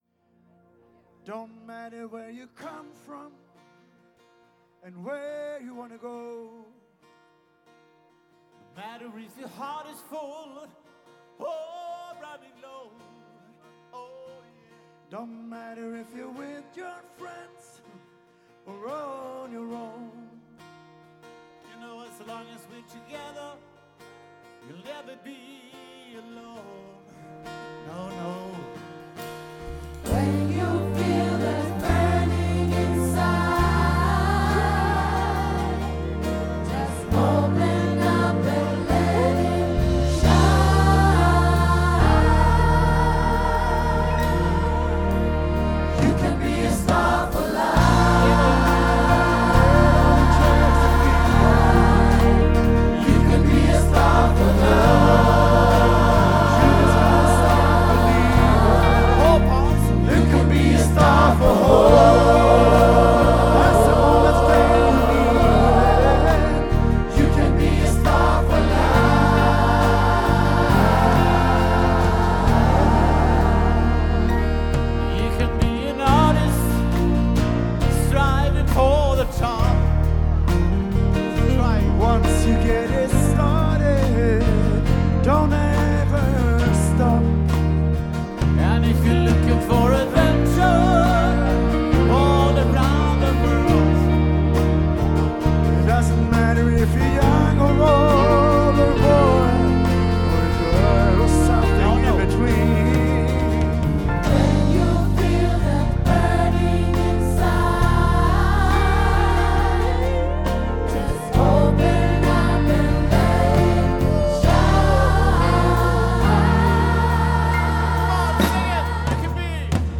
A_star_for_life-Fira_livet-2_sep-23_Malmo.mp3